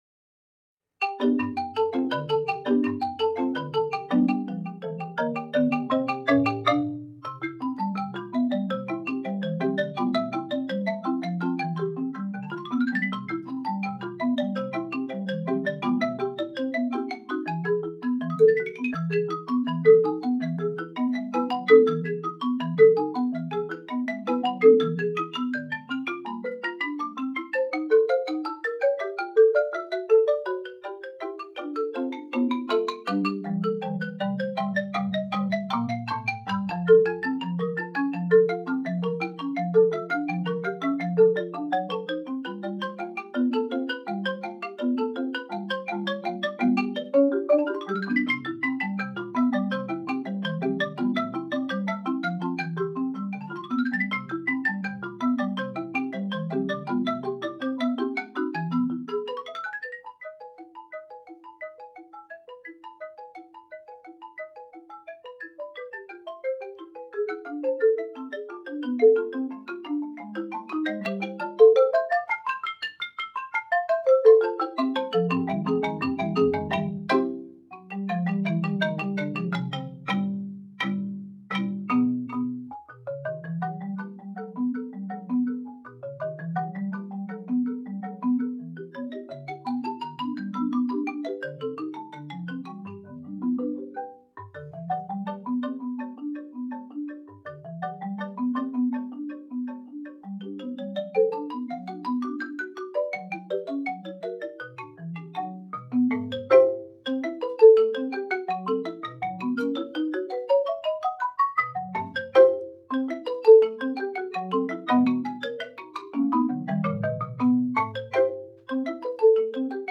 arrangement pour xylophone et marimba  (juillet 2019)
marimba
xylophone
fichier final (format .mp3) a été obtenu à partir de l'enregistrement en multipliant la vitesse par 1,4 afin de s'approcher du tempo cible.